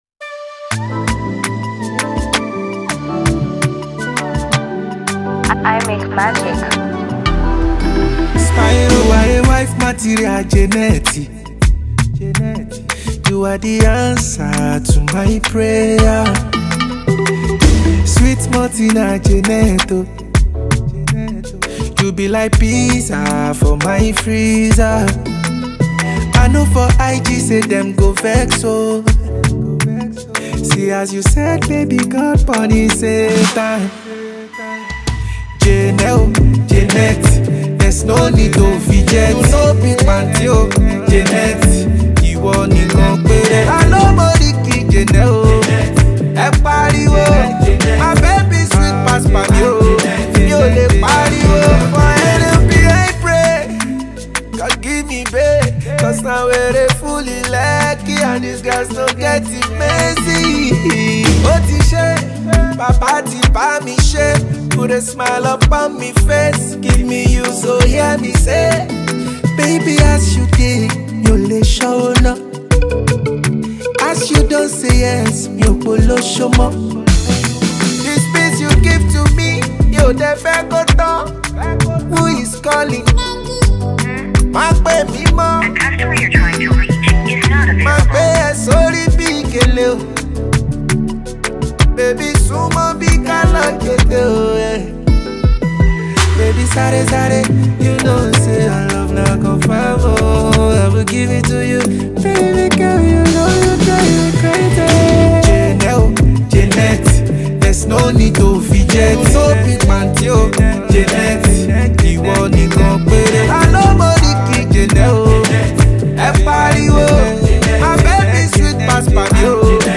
and modern Afrobeat rhythms.
love song